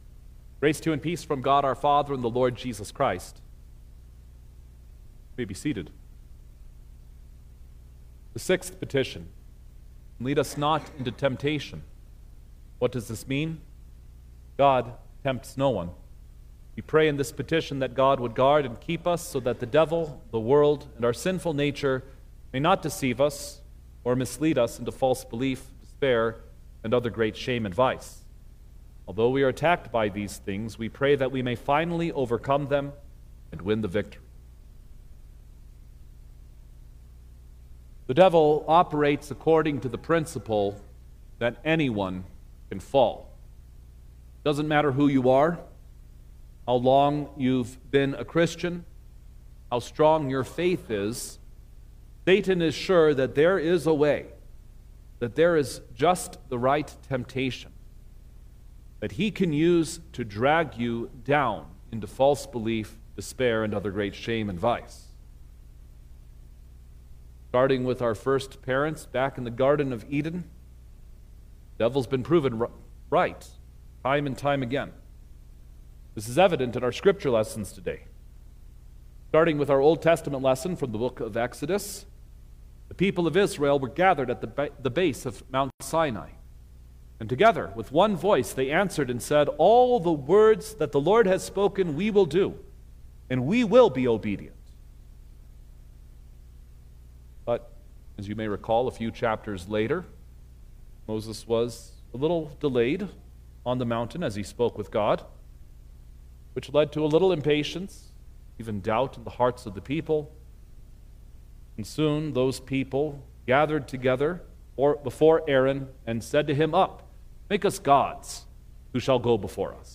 April-2_2026_Maundy-Thursday_Sermon-Stereo.mp3